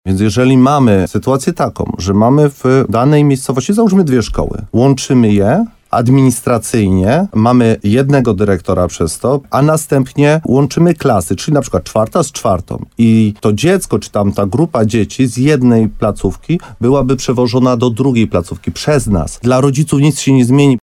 Tak o planowanej reformie szkolnej w gminie Mszana Dolna mówi wójt tego samorządu Mirosław Cichorz. Gość programu Słowo za Słowo na antenie RDN Nowy Sącz tłumaczył, że żadna decyzja jeszcze nie zapadła.